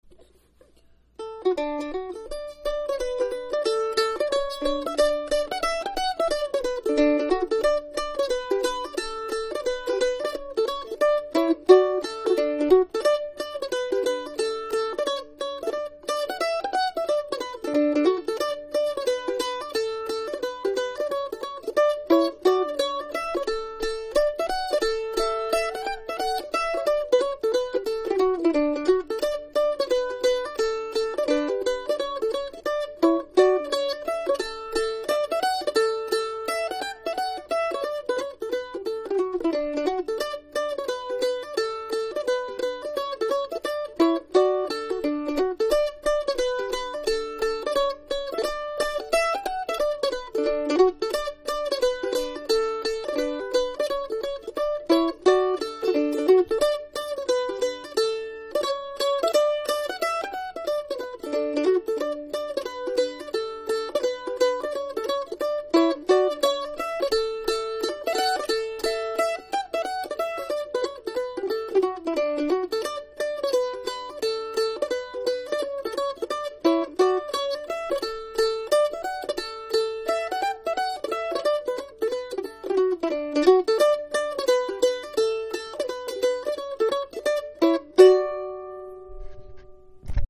Morris & Country Dance